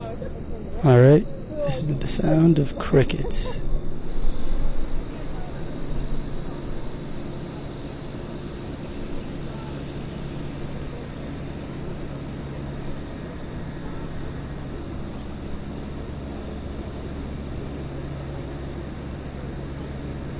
大学校园里的蟋蟀
描述：蟋蟀唧唧喳喳，而大学生可以在后台轻轻地听到。用电容麦克风录制。
Tag: 性质 蟋蟀 安静-喃喃